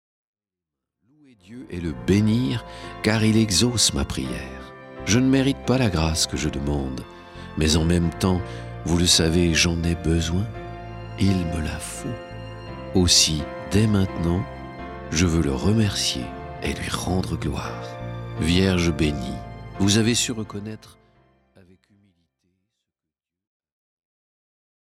Chant